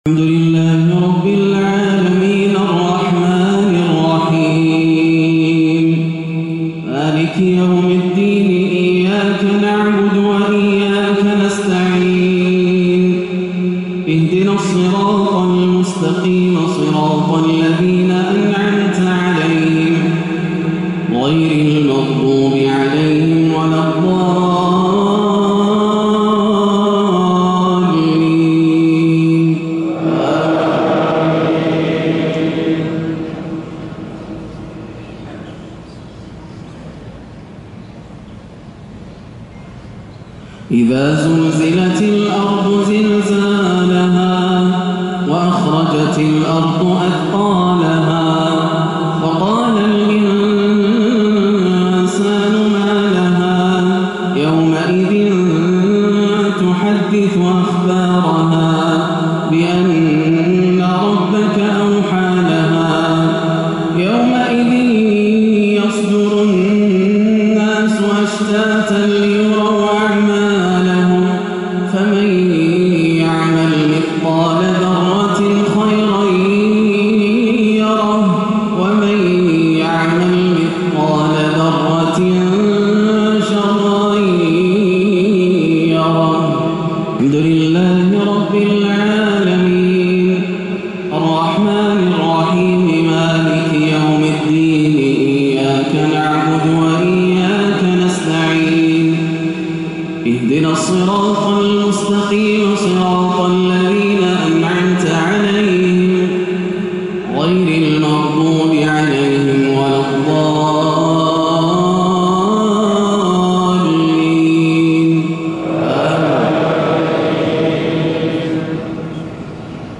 سورتي الزلزلة والتكاثر بتلاوة عجمية رائعة - صلاة الجمعة 6-8 > عام 1437 > الفروض - تلاوات ياسر الدوسري